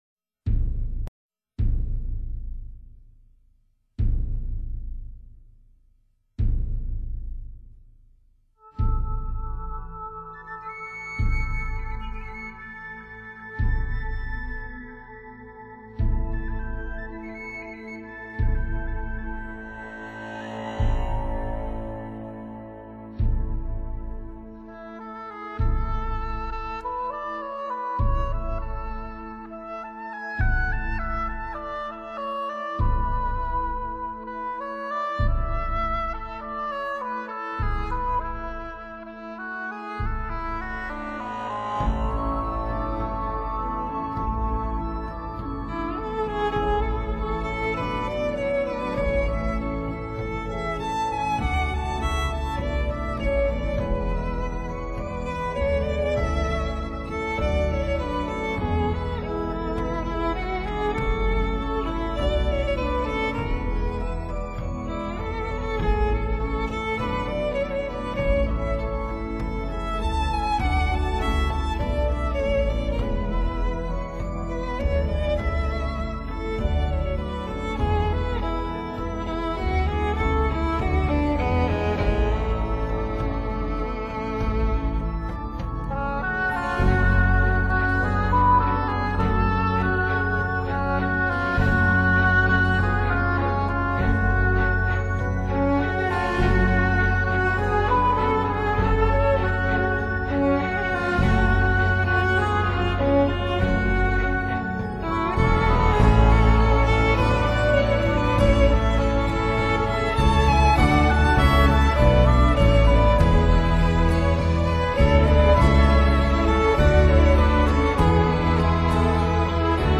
정말 축복받은 듯한 느낌이 들 겝니다...